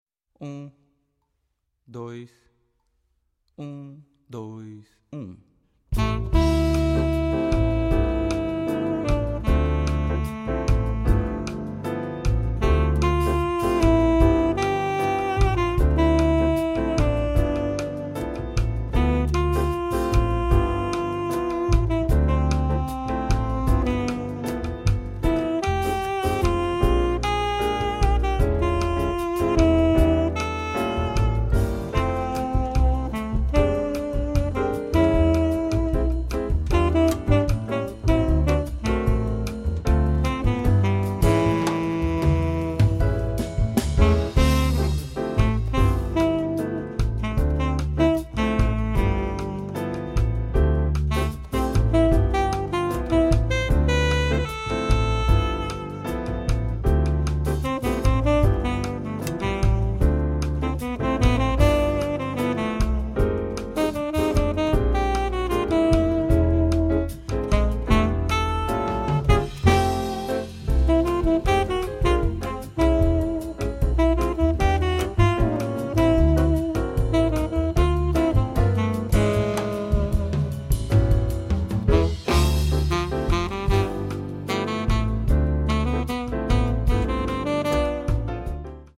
Voicing: Soprano and Tenor Sax w/ Audio